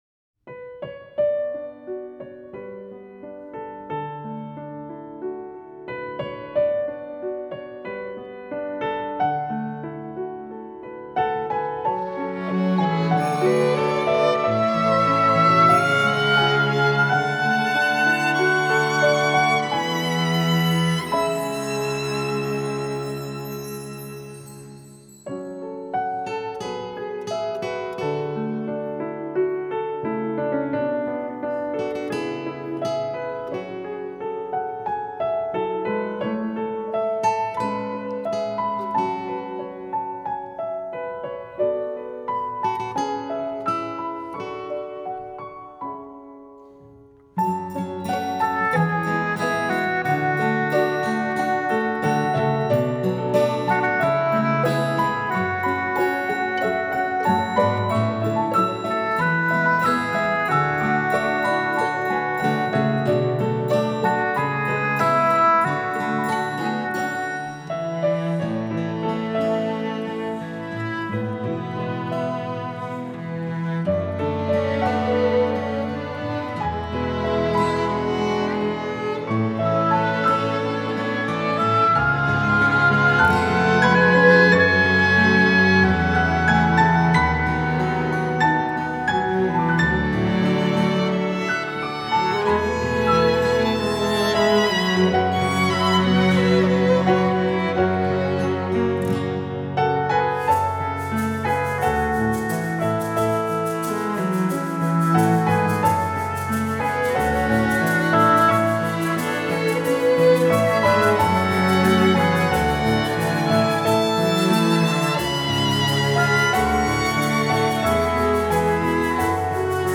موسیقی اینسترومنتال موسیقی بیکلام